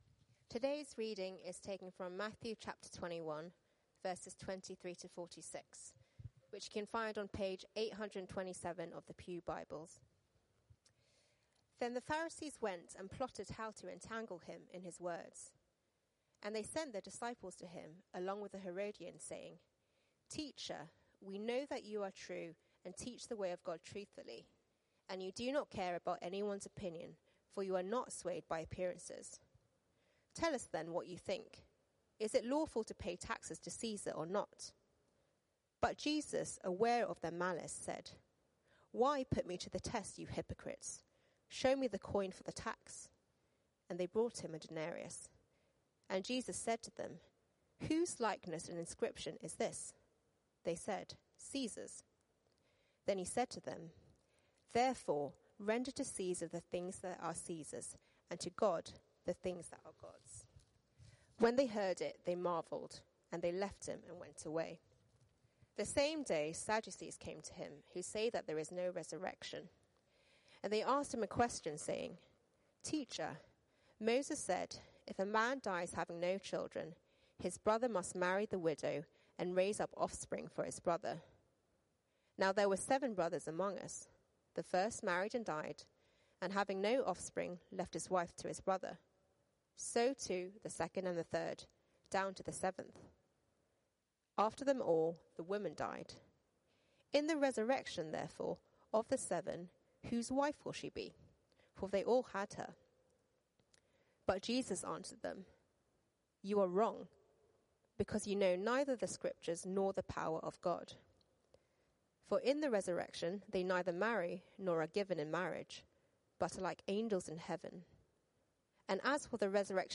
A talk recording from our series in Matthew's gospel, at Euston Church